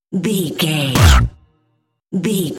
Dramatic hit deep electronic wood
Sound Effects
Atonal
heavy
intense
dark
aggressive